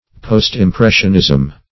Post-impressionism \Post`-im*pres"sion*ism\, n. (Painting)